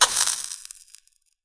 fireball_impact_sizzle_burn1.wav